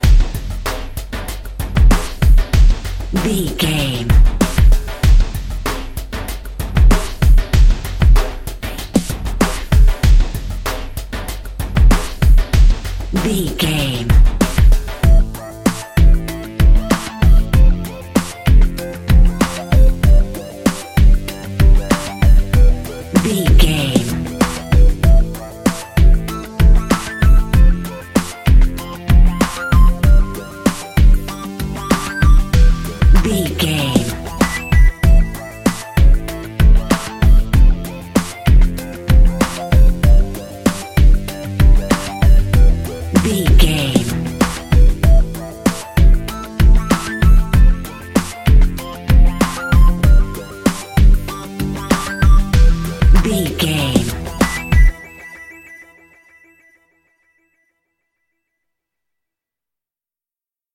Aeolian/Minor
drums
electric piano
strings
funky